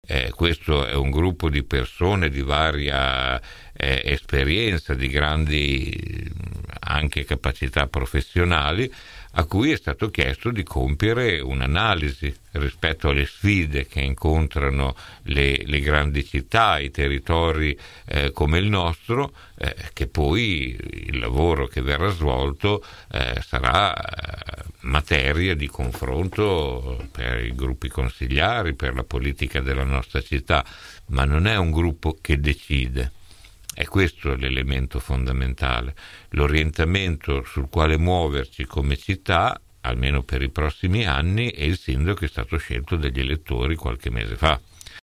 17 nov. – Questa mattina ai nostri microfoni l’assessore all’urbanistica, ambiente e sport del comune di Bologna Maurizio Degli Esposti ha risposto su una serie di punti a cominciare dalla questione dei “23 saggi” chiamati a “riprogettare Bologna” che, afferma l’assessore, non hanno alcun potere decisionale